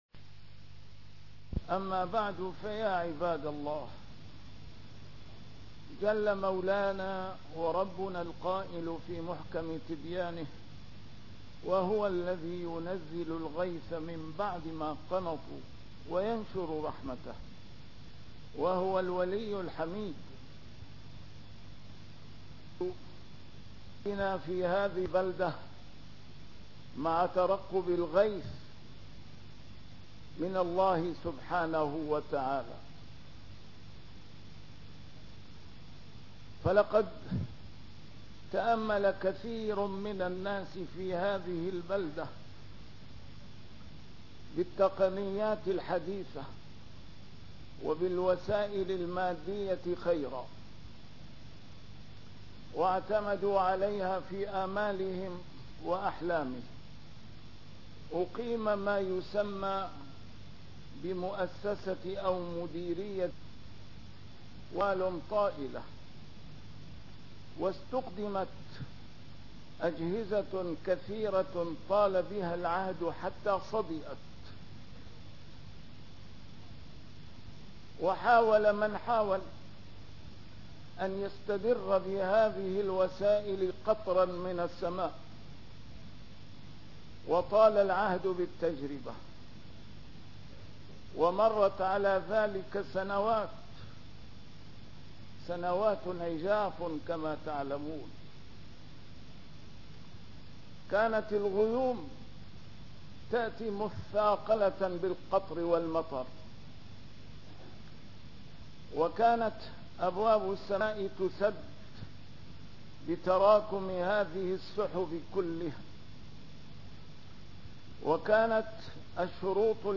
A MARTYR SCHOLAR: IMAM MUHAMMAD SAEED RAMADAN AL-BOUTI - الخطب - خلاصة قصتنا في هذه البلدة مع ترقب الغيث